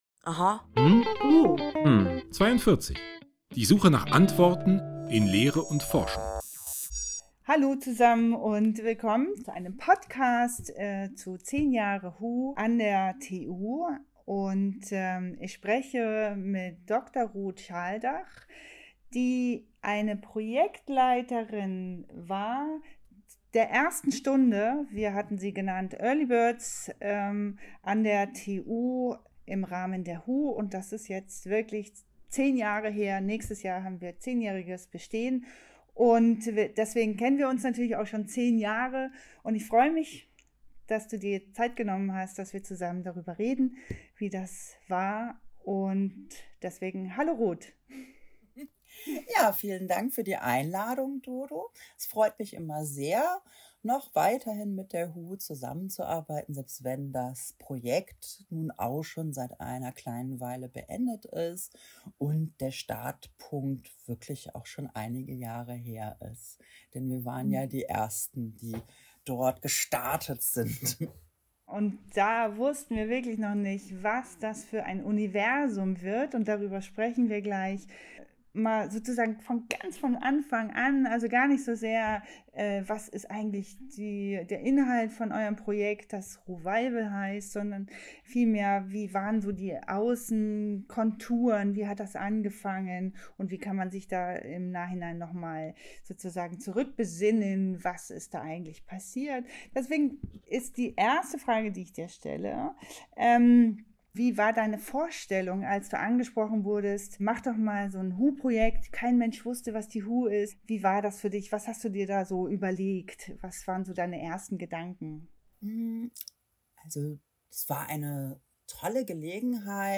In unserem Gespräch blicken wir zurück auf 10 Jahre produktive Zusammenarbeit.